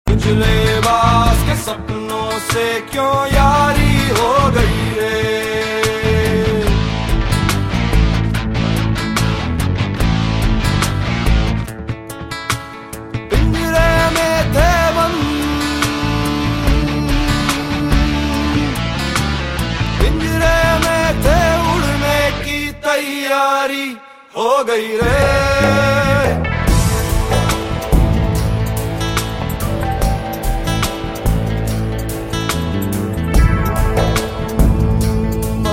File Type : Bollywood ringtones